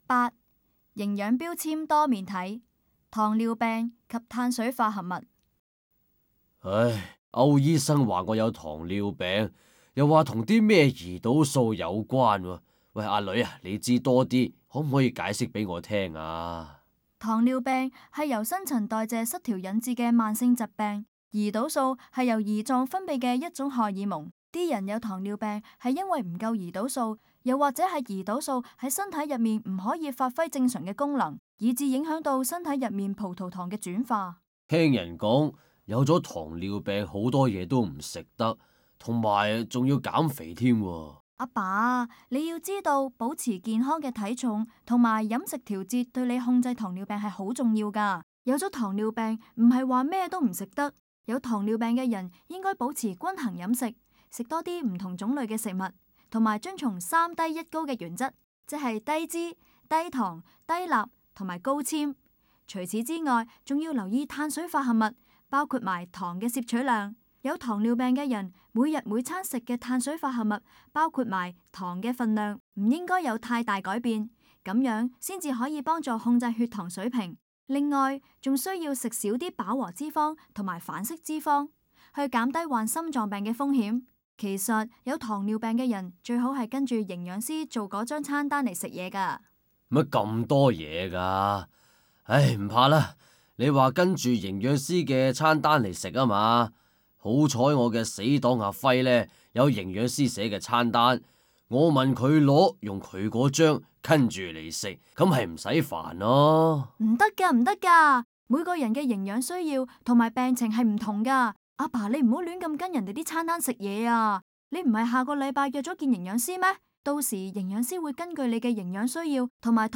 VIII. 錄音短劇